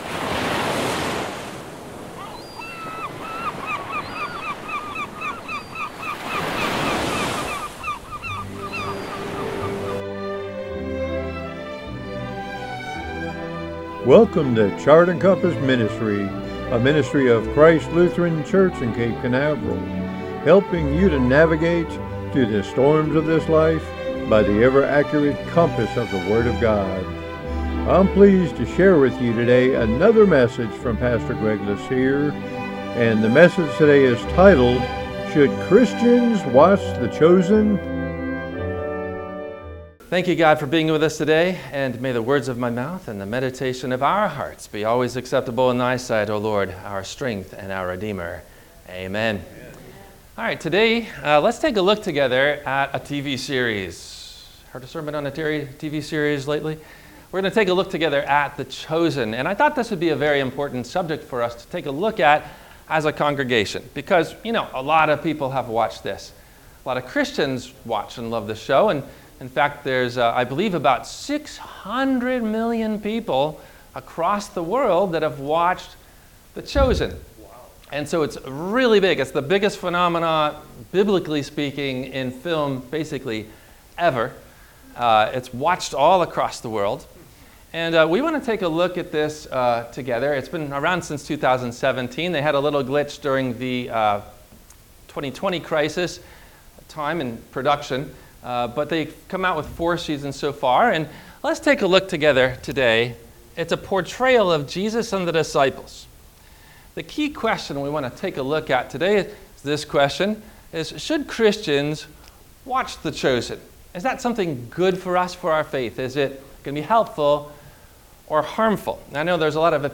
WMIE Radio – Christ Lutheran Church, Cape Canaveral on Mondays from 12:30 – 1:00